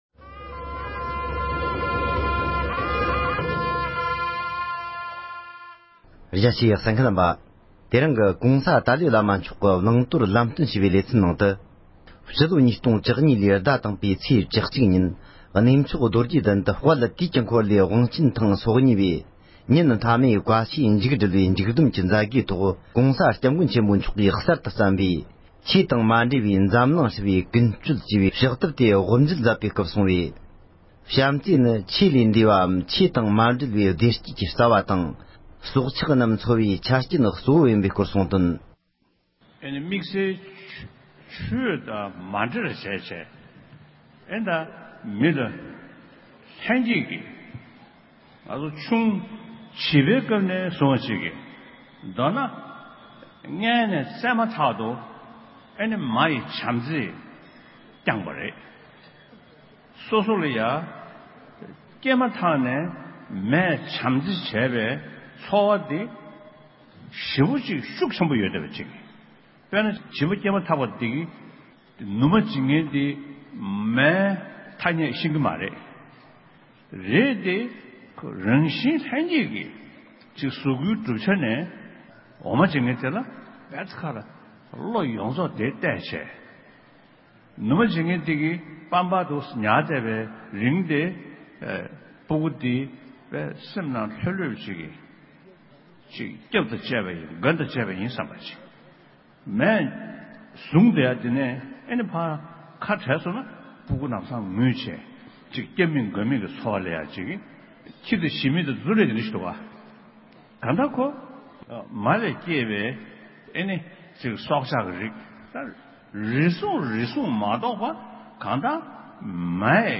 ༸གོང་ས་མཆོག་གིས ཆོས་ལུགས་ཀྱི་ས་མཚམས་ལས་འདས་པའི་འཛམ་གླིང་སྤྱི་ལ་སྨན་པའི་བཟང་སྤྱོད་ཐད་བསྩལ་བའི་བཀའ་སློབ།